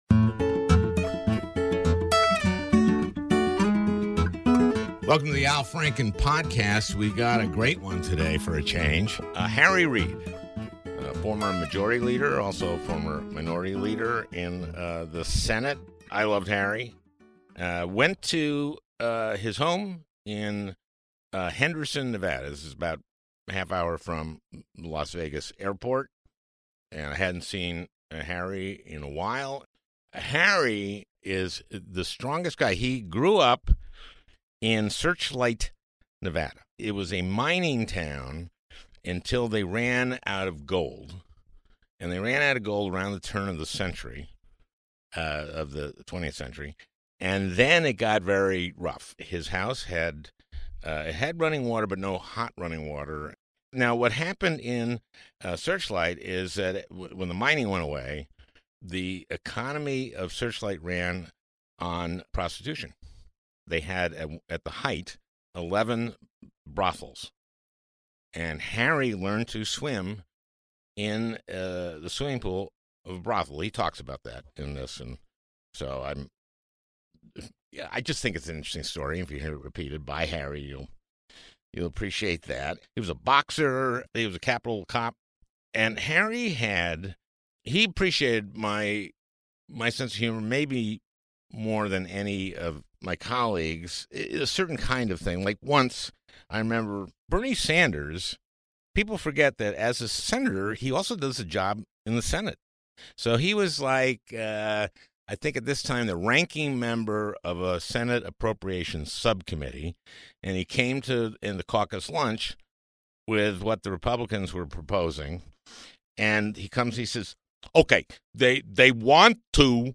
A Conversation with Former Senate Majority Leader Harry Reid
Al travels to Leader Reid’s home in Henderson, Nevada, to discuss his life and career, with a special focus on their relationship during the years he and Al overlapped in Senate.